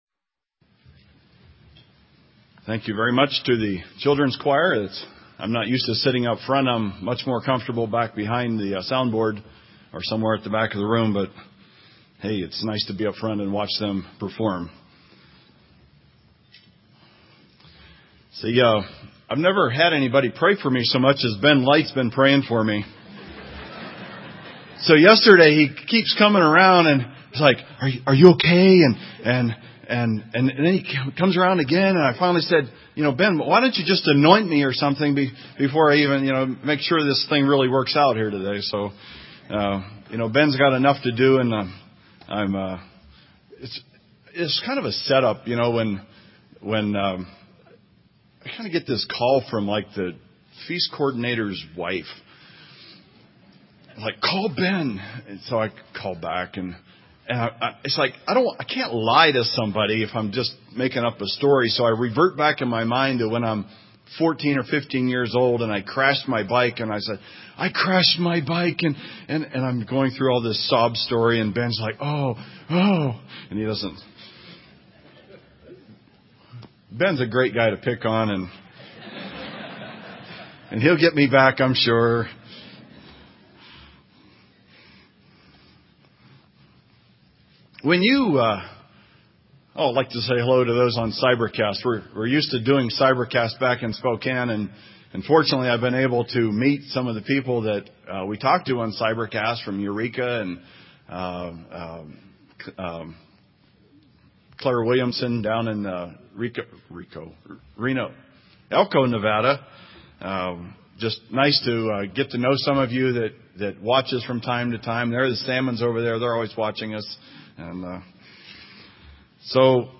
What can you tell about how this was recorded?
This sermon was given at the Bend, Oregon 2012 Feast site.